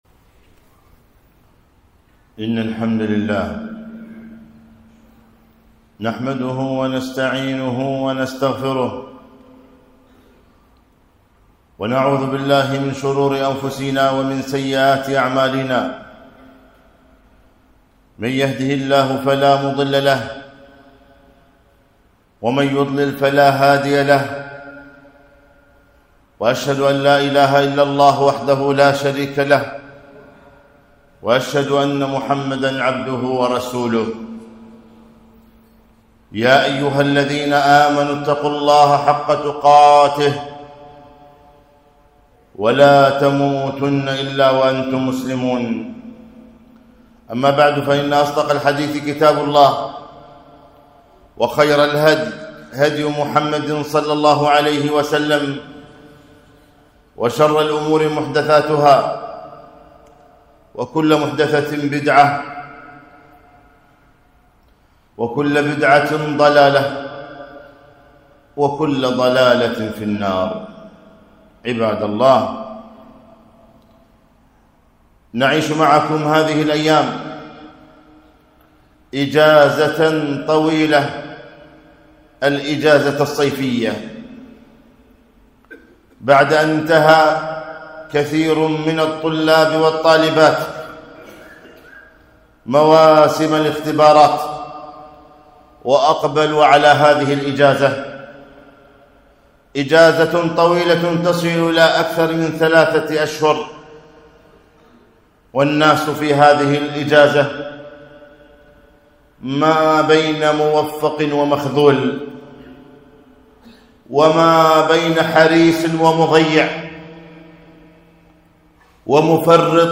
خطبة - اغتنم الإجازة الصيفية